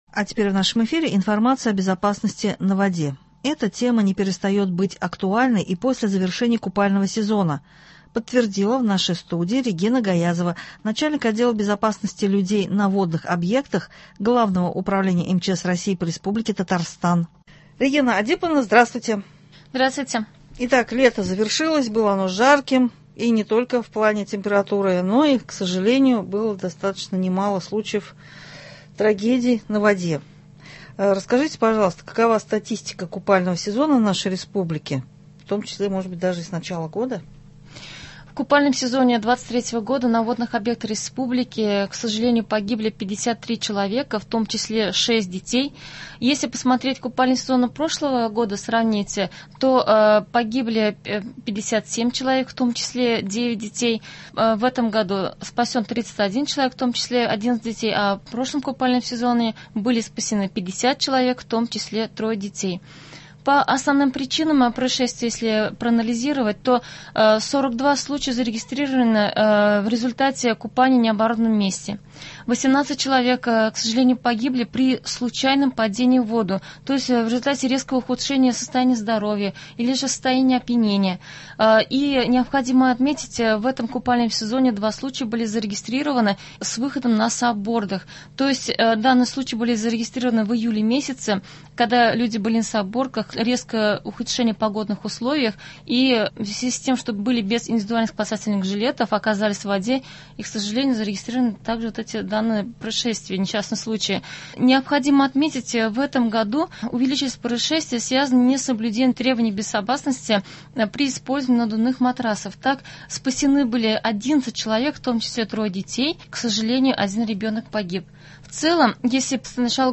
Безопасность на воде — эта тема не перестает быть актуальной и после завершения купального сезона – подтвердила в нашей студии